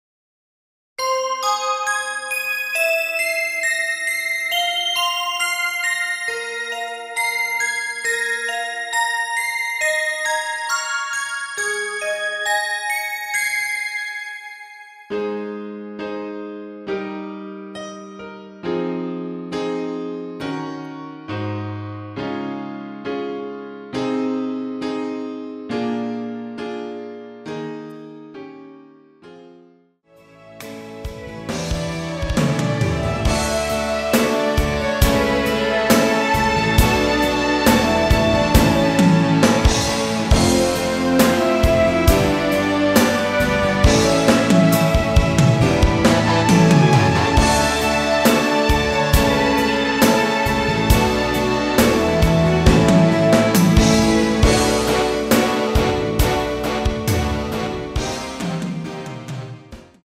MR입니다.
Ab
앞부분30초, 뒷부분30초씩 편집해서 올려 드리고 있습니다.
중간에 음이 끈어지고 다시 나오는 이유는